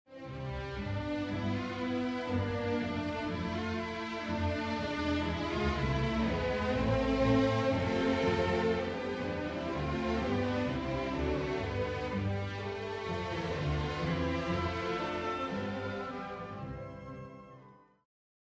De eerste keer hoor je een fragment, zonder begeleidende akkoorden. Het stuk lijkt zowel bij de eerste leidtoon-grondtoon, als bij de laatste twee keer leidtoon-grondtoon af.
Pas op het einde wel.